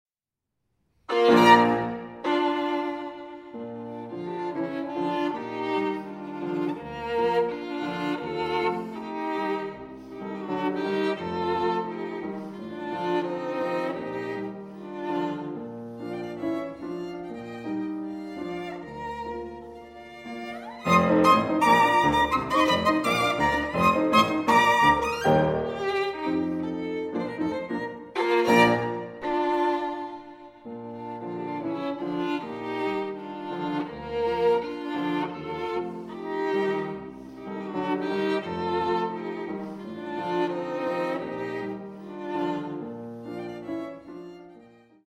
for violin and piano
violin
piano